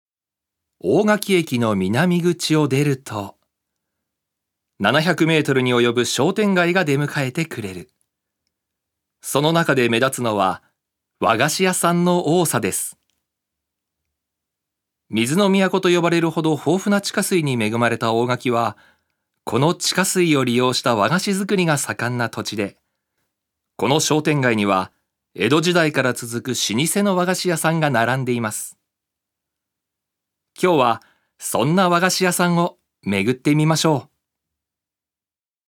所属：男性タレント
ナレーション１